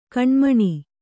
♪ kaṇmaṇi